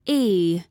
Коллекция включает четкое произношение всех 26 букв, что идеально подходит для обучения детей или начинающих.
Ee i: